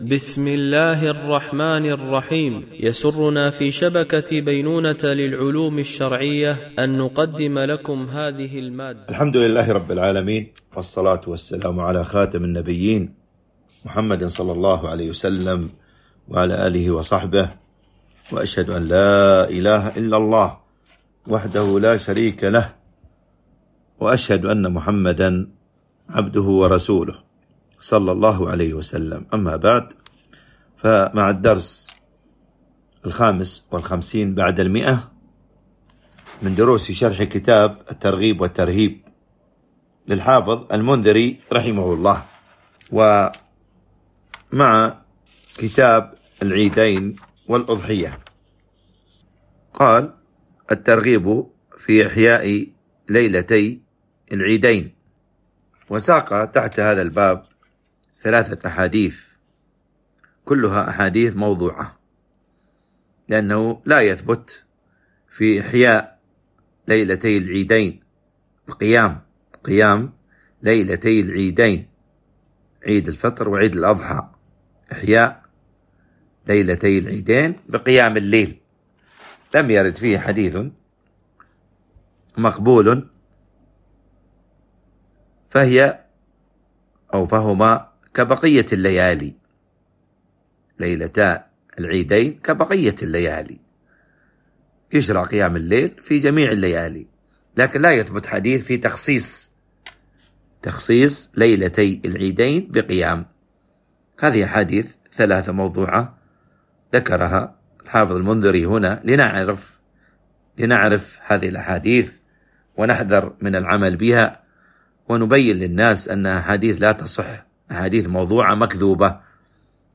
التنسيق: MP3 Mono 44kHz 64Kbps (VBR)